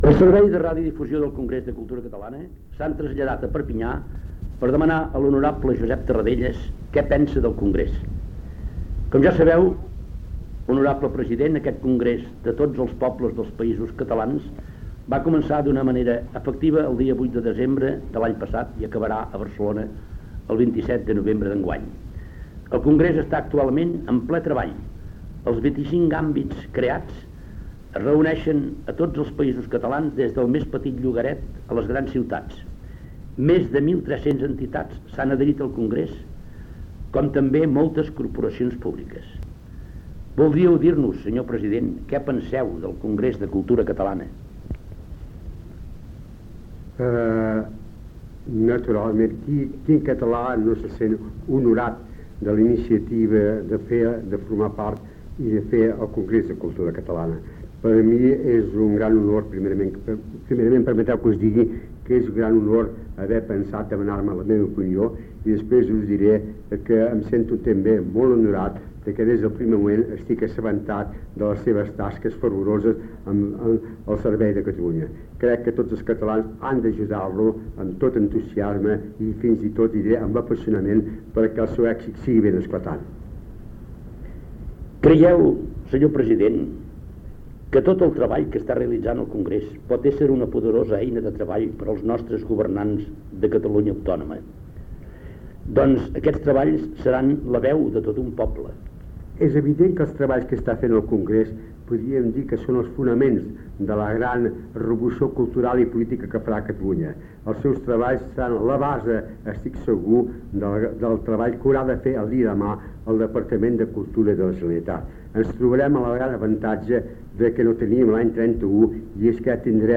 El Servei de Radiodifusió del Congrés de Cultura Catalana entrevista al president de la Generalitat a l'exili Josep Tarradellas, a Perpinyà, sobre el Congrés de Cultura Catalana
Informatiu